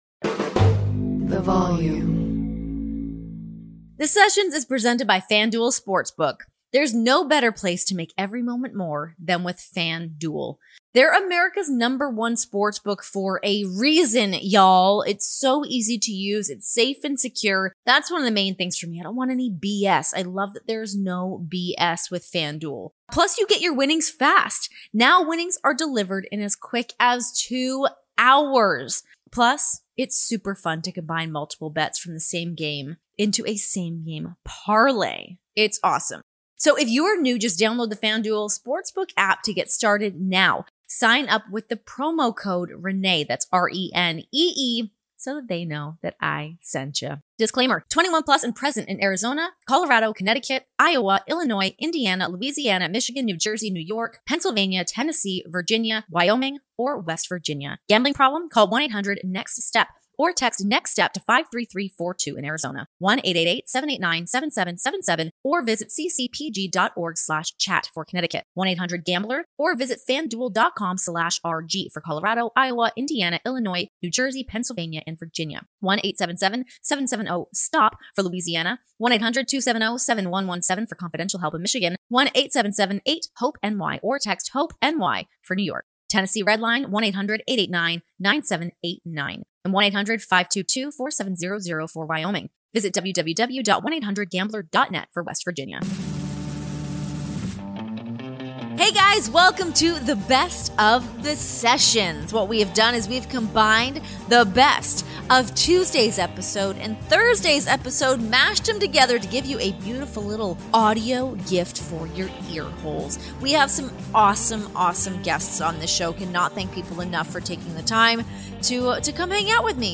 Relive the best of the Sessions this week, featuring highlights from Renee's chats with Johnny Gargano and Swoggle.